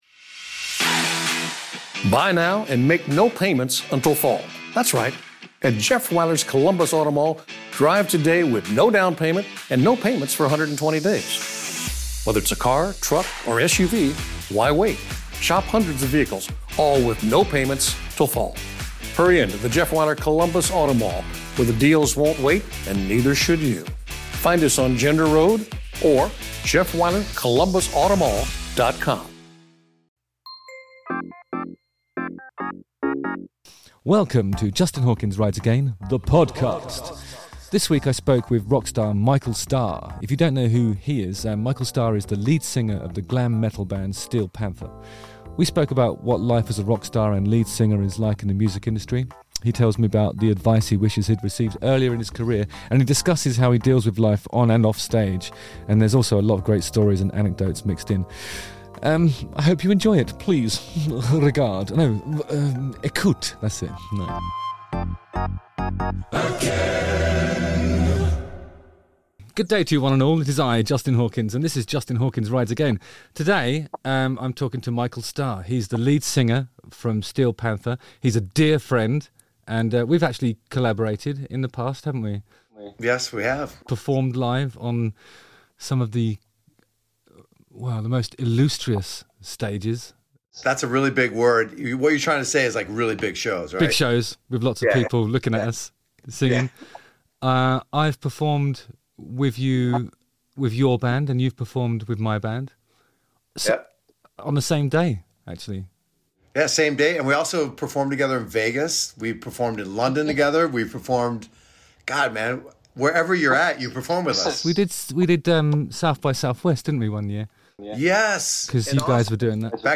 This week I spoke with rockstar Michael Starr!